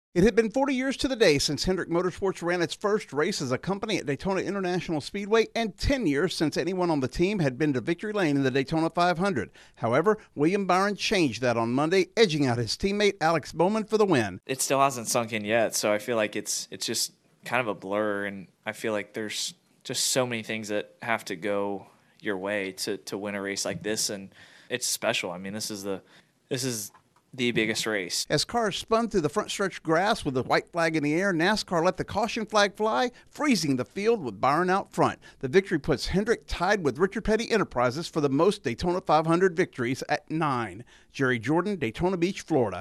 William Byron wins NASCAR's rain-delayed season opener. Correspondent